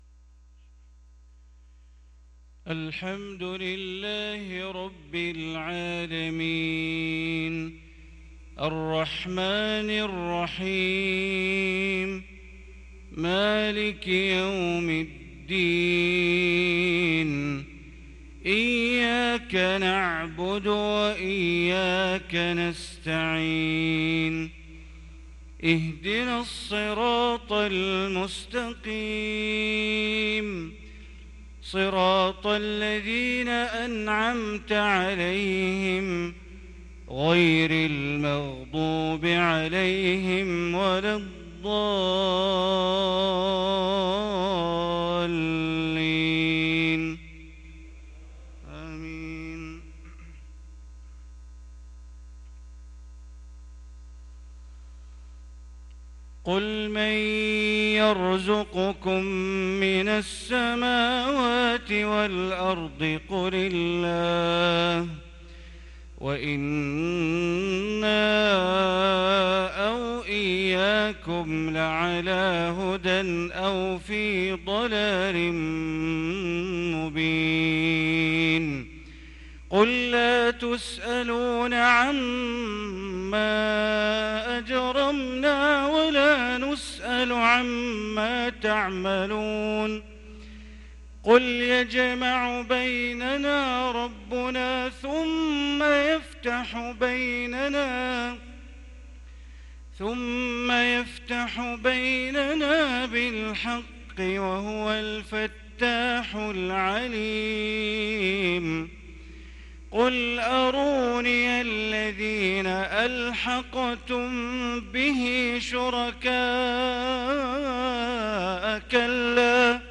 صلاة المغرب للقارئ بندر بليلة 11 ربيع الأول 1444 هـ